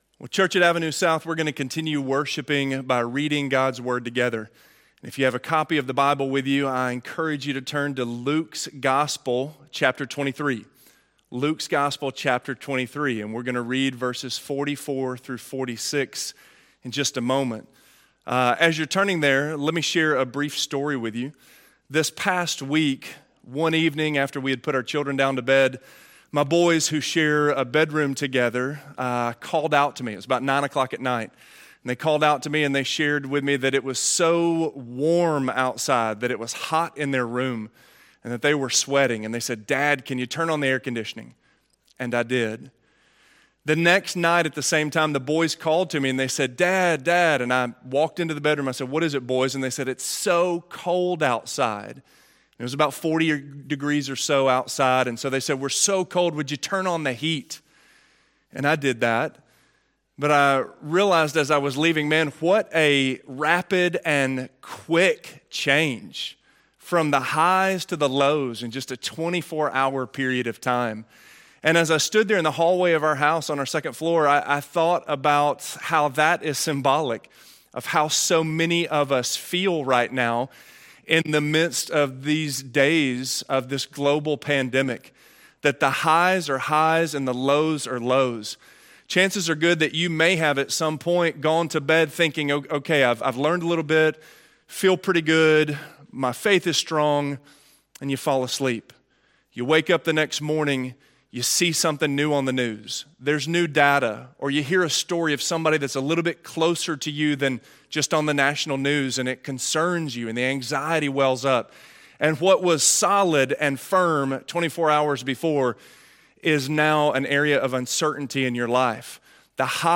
In To Your Hands I Commit My Spirit - Sermon - Avenue South